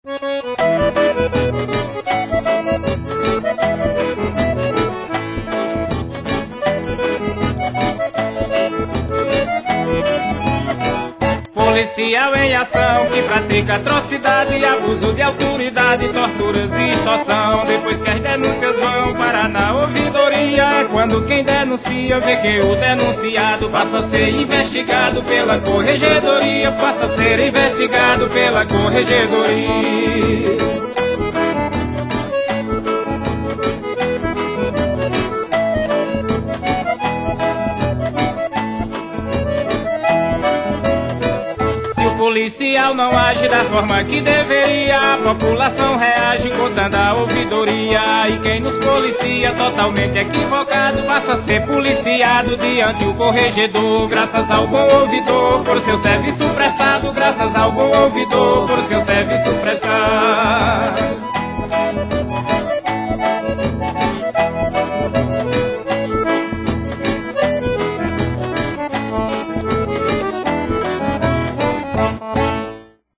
Faixa 3 - Spot Forró 1 Faixa 10 - Spot Raiz 2